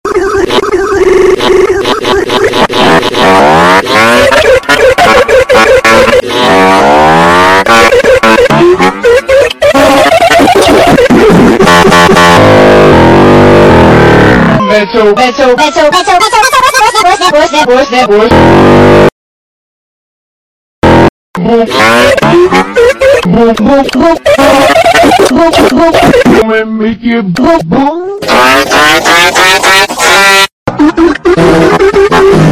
Brain Fart Extended Youtube Poop (soundboard) Sound Button - Free Download & Play